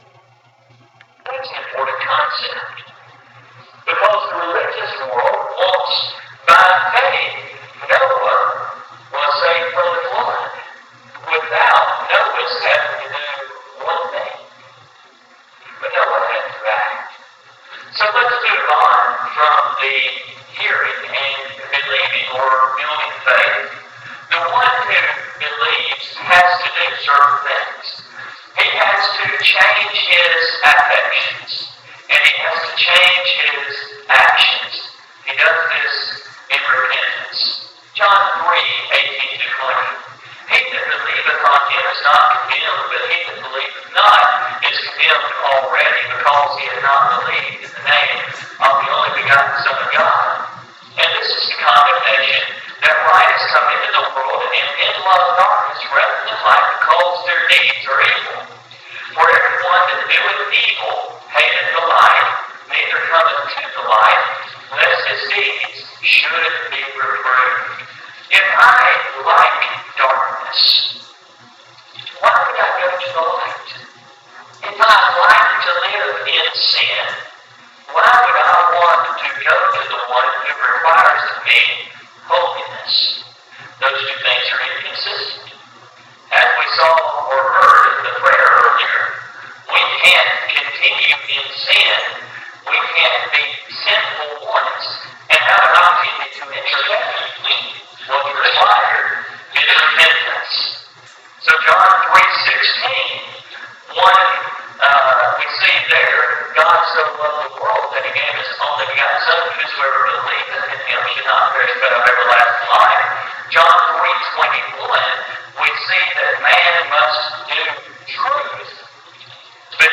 2015-Summer-Sermons-2b.mp3